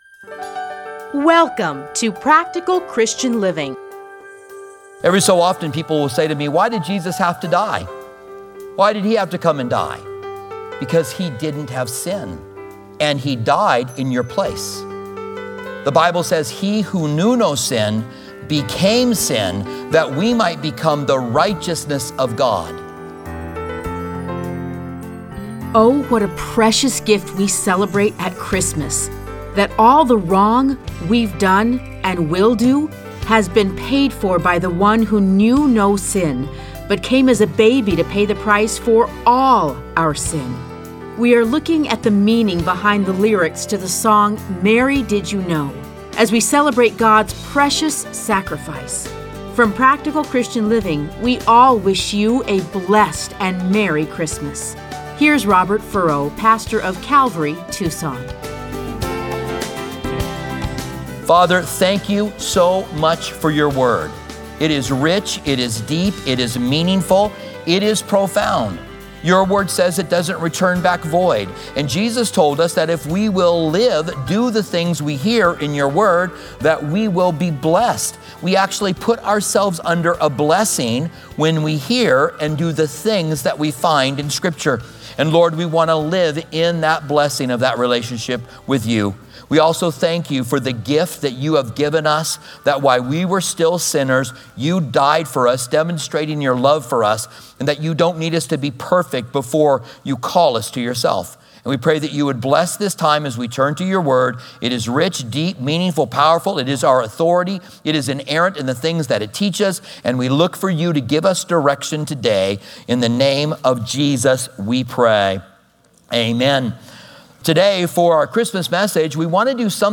Listen to a teaching for Christmas.